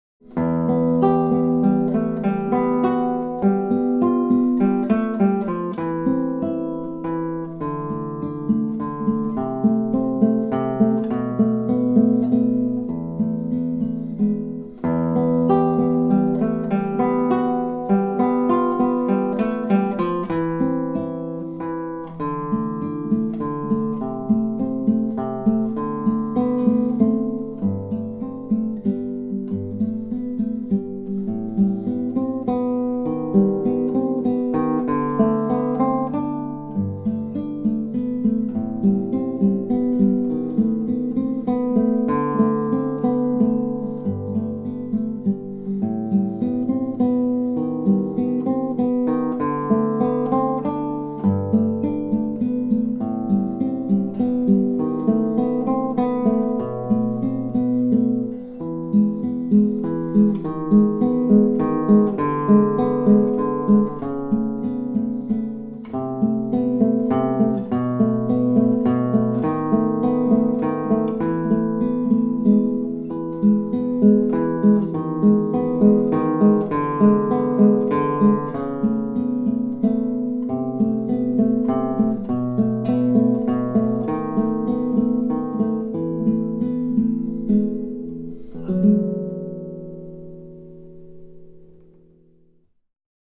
promenade (moyen) - Guitare Classique
Calme, posé....
Voila une bien jolie pièce, ciselée avec une précision d'orfèvre.
Chaque note résonne librement, avec une force égale.
J'ai l'impression d'entendre un dialogue de cordes.
Et puis il y a cette superbe mélodie finale qui vient nous caresser les oreilles si agréablement...